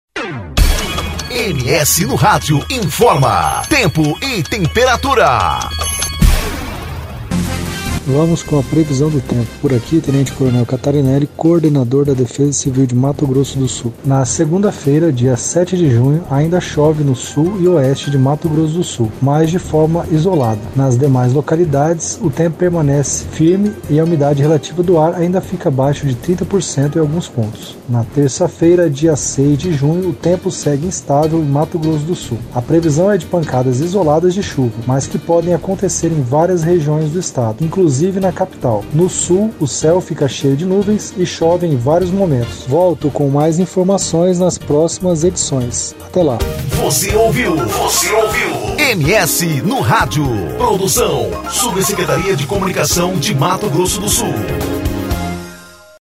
Previsão do Tempo com o coordenador estadual de Defesa Civil Tenente Coronel Fábio Catarineli, para o fim de semana.